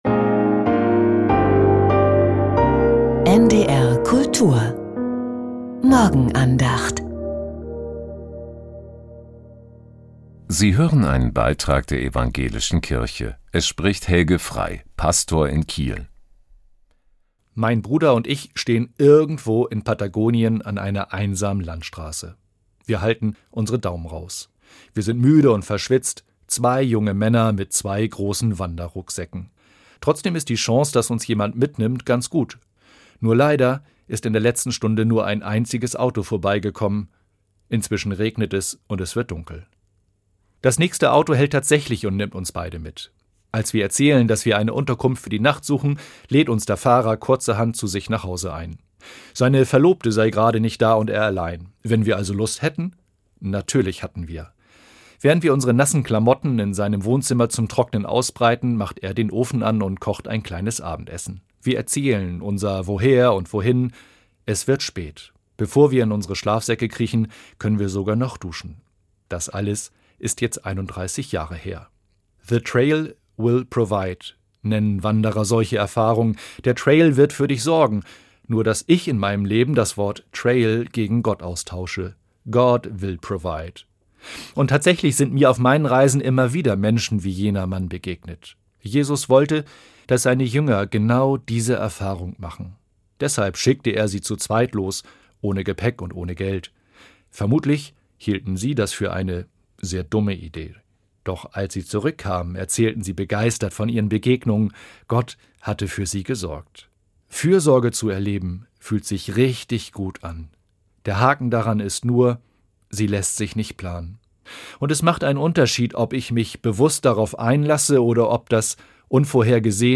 Die Andachten waren auf NDR Info und NDR Kultur zu hören.